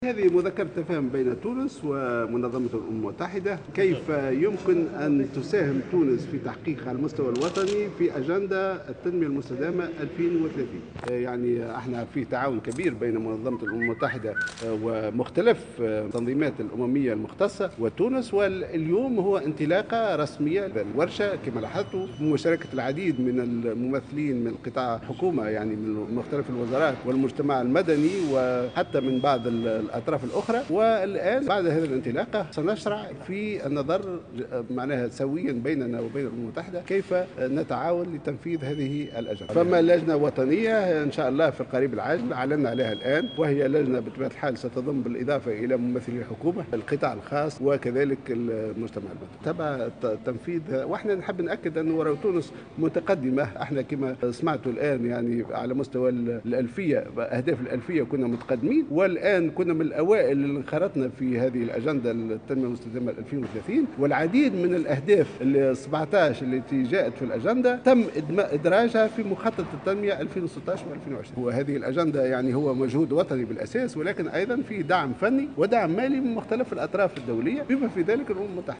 وأكد الجهيناوي في تصريح لمبعوث الجوهرة أف أم أن ورشة عمل وطنية انتظمت صباح اليوم للإعلان عن الإنطلاق الرسمي في تنفيذ هذه الأجندة مضيفا أنه سيتم النظر في كيفية التعاون لتحقيق الأهداف التي تضمنتها والتي وقع إدراجها في مخطط التنمية.